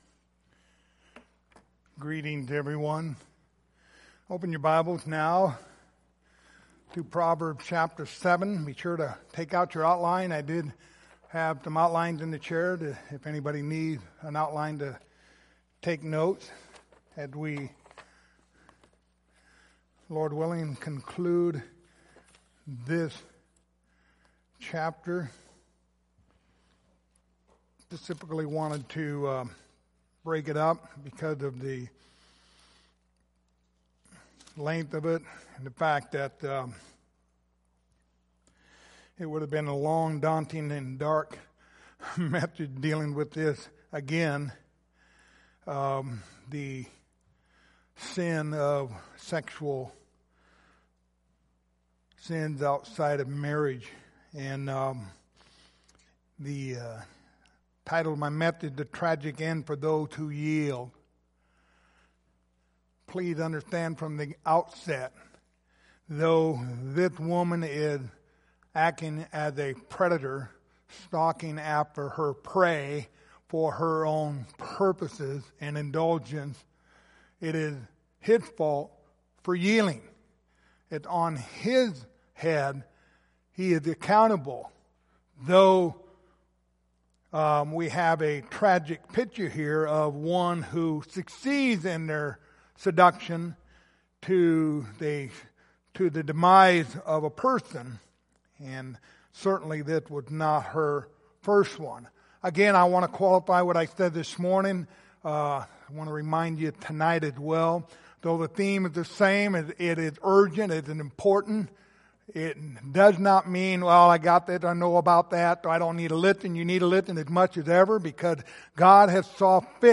Passage: Proverbs 7:13-27 Service Type: Sunday Evening